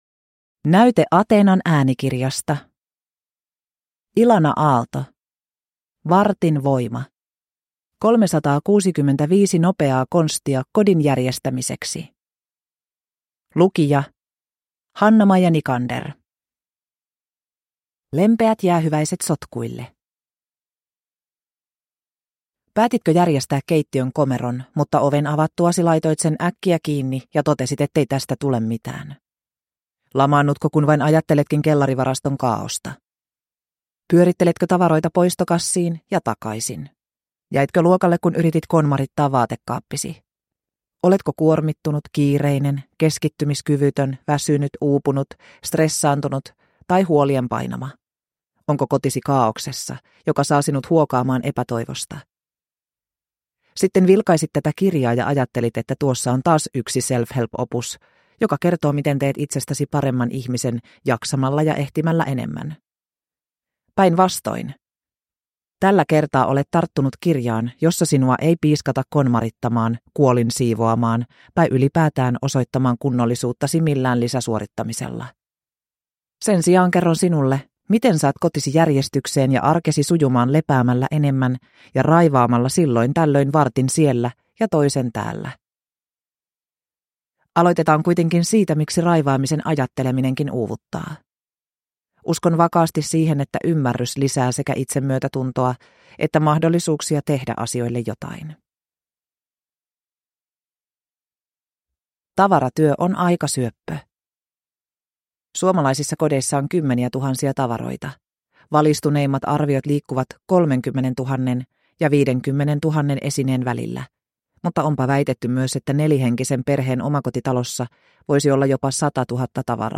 Vartin voima – Ljudbok